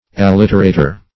Search Result for " alliterator" : Wordnet 3.0 NOUN (1) 1. a speaker or writer who makes use of alliteration ; The Collaborative International Dictionary of English v.0.48: Alliterator \Al*lit"er*a`tor\, n. One who alliterates.